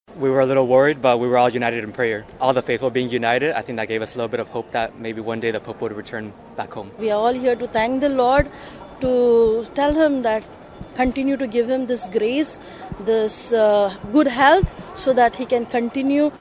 These people have been gathering in St Peter's Square to welcome him home.